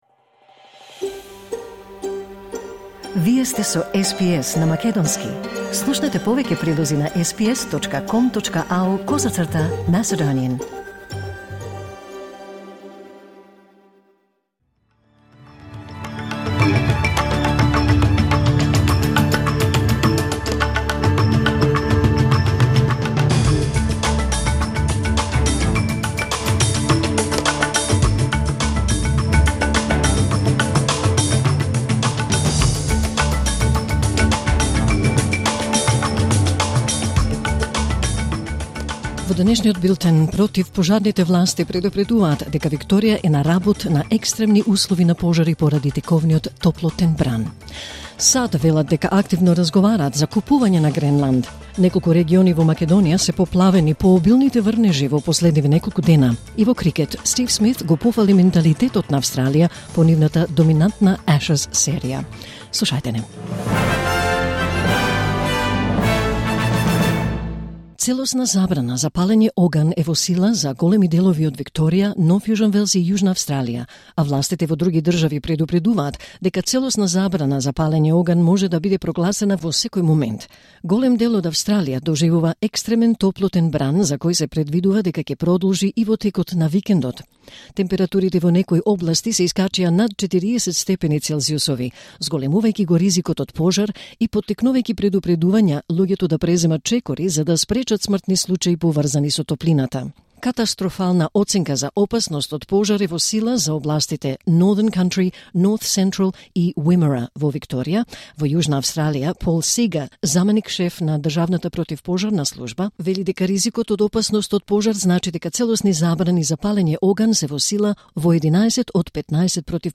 Вести на СБС на македонски 9 јануари 2026